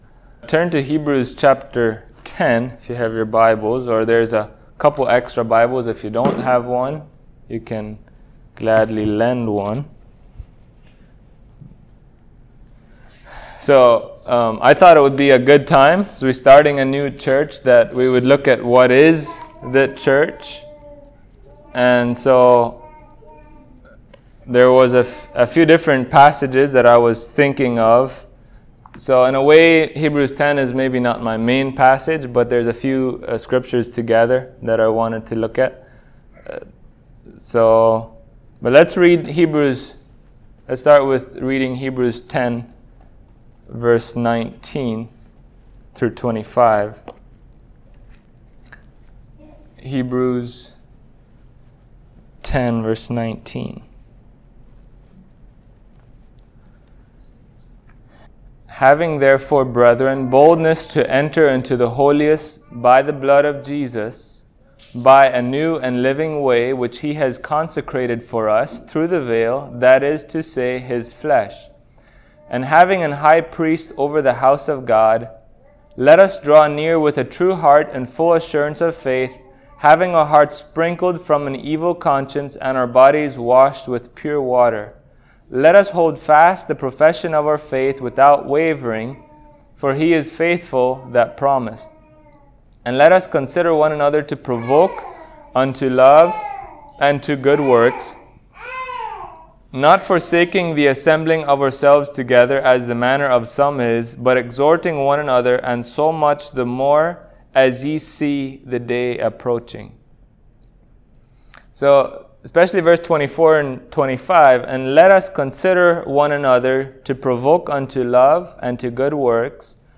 Passage: Matthew 16:16-19 Service Type: Sunday Morning Topics